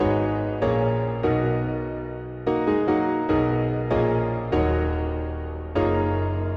描述：二手Nexus Grand
Tag: 146 bpm Electro Loops Piano Loops 1.11 MB wav Key : Unknown